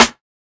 Lifestyle Snare.wav